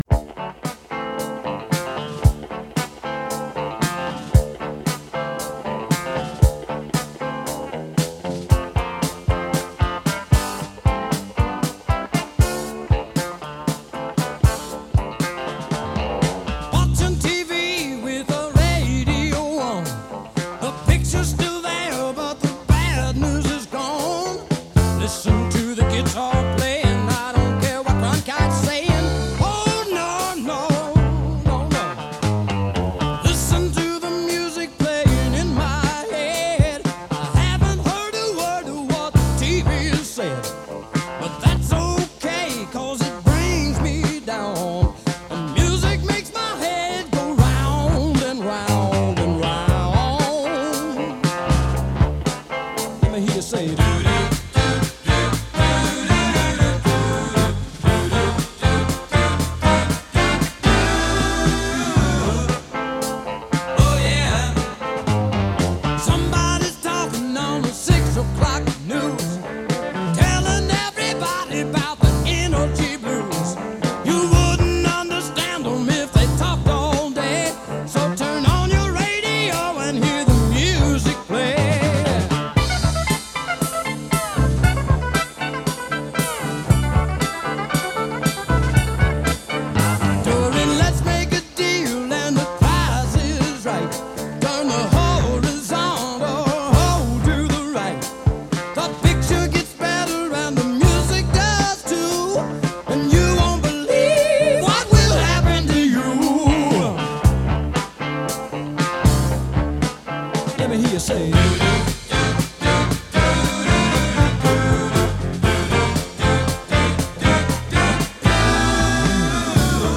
Rock sureño en EE.UU.